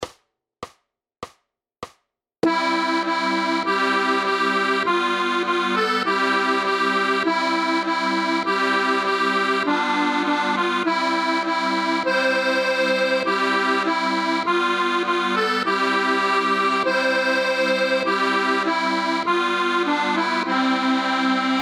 Formát Akordeonové album
Hudební žánr Vánoční písně, koledy